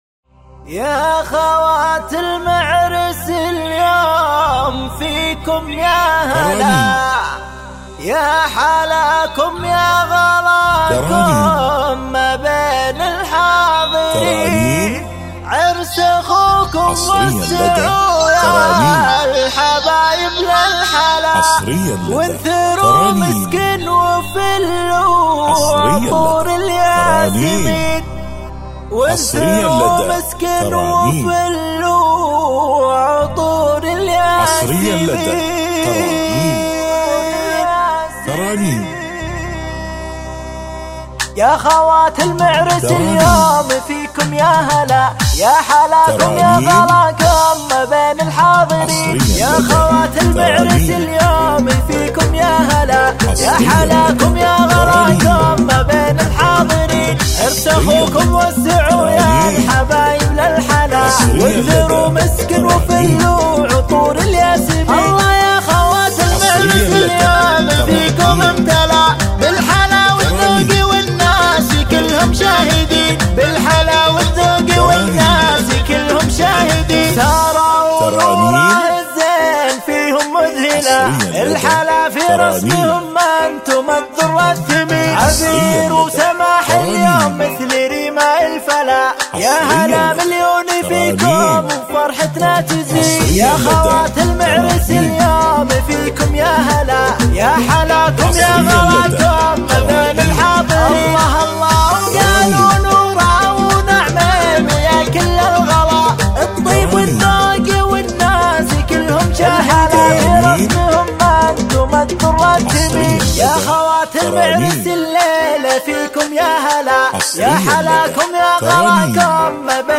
زفة شيلات
بدون موسيقى